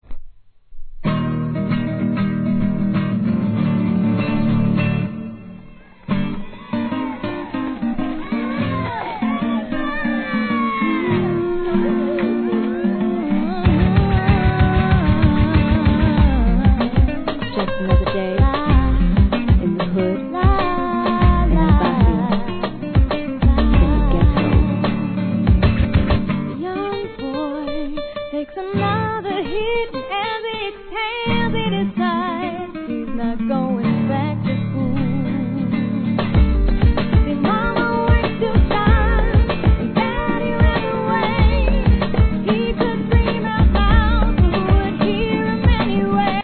HIP HOP/R&B
個性的世界観ありまくりR&B。